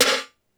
{Snr} Tm88 Super Verb Snare.wav